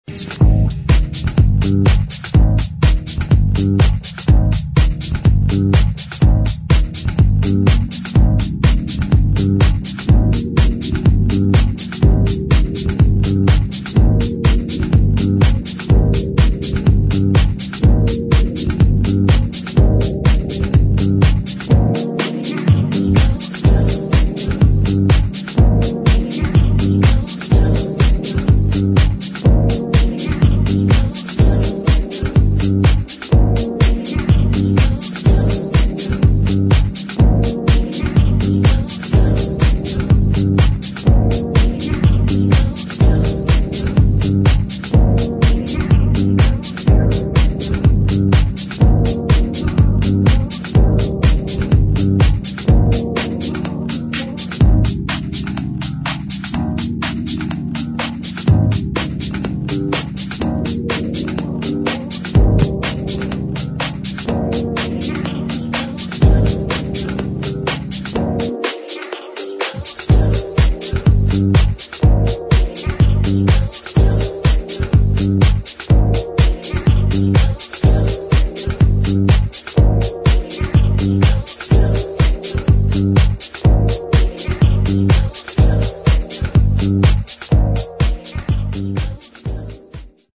[ DEEP HOUSE / NY HOUSE ]